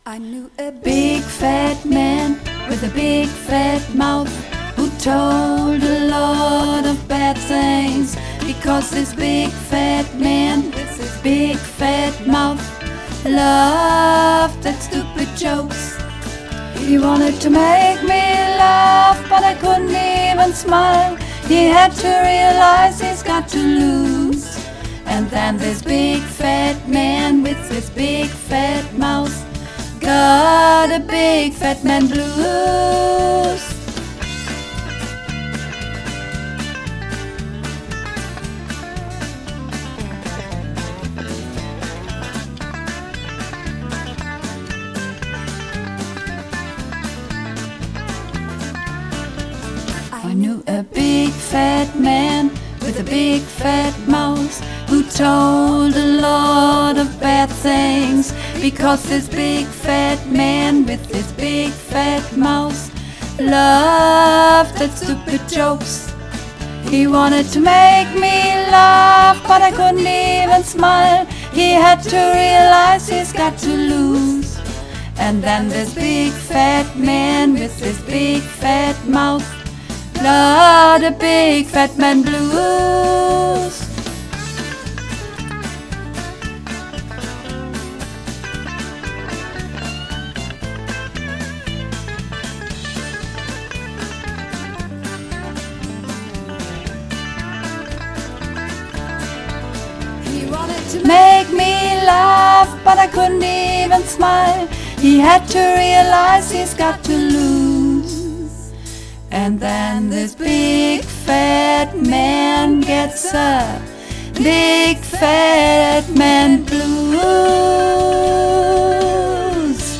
Übungsaufnahmen - Big Fat Man